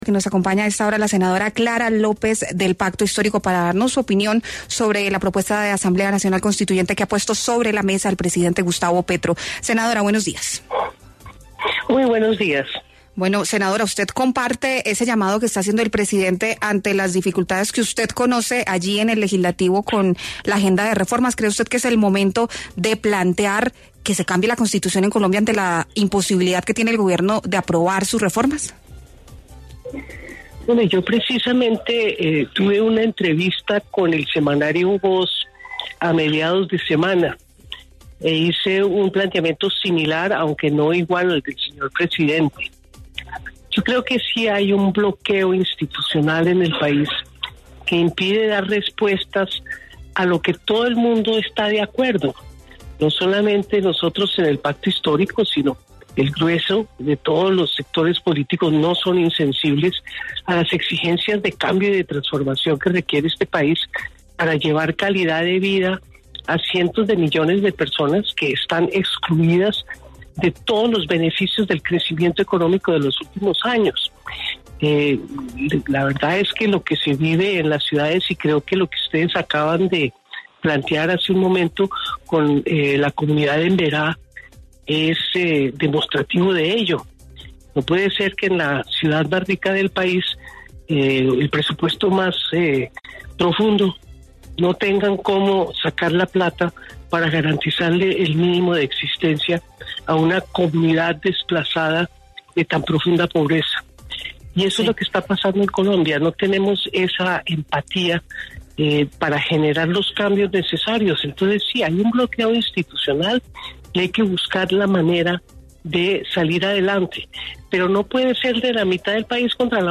La senadora del Pacto Histórico, Clara López Obregón, en debate en La W Radio con el congresista del uribismo Miguel Uribe Turbay salió al paso de las críticas agresivas de la oposición  asegurando que es evidente que existe un bloqueo institucional en el país que impide dar respuesta a las exigencias de cambio y transformación que requiere Colombia para llevar a cabo y ofrecer mecanismos de calidad de vida a las personas que históricamente han “sido excluidas del crecimiento económico de los últimos años”.
Las declaraciones de la senadora Clara López en el siguiente audio: